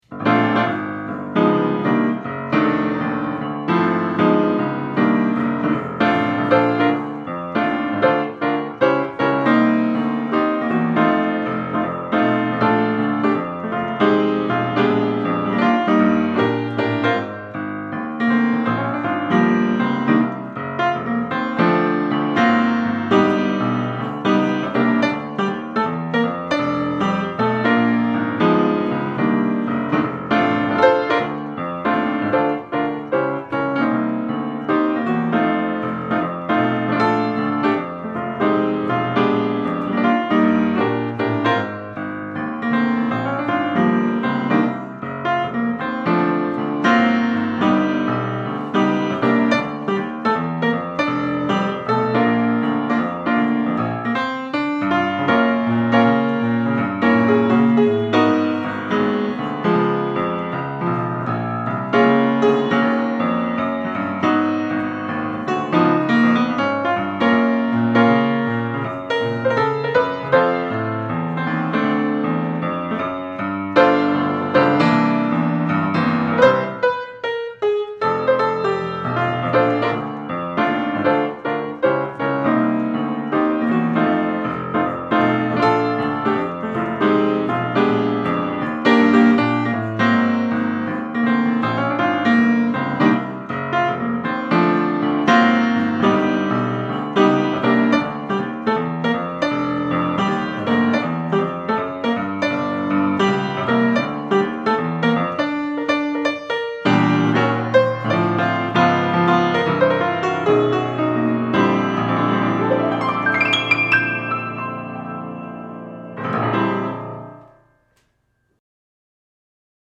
Easy Listening
Cocktail Music
Piano Jazz , Solo Piano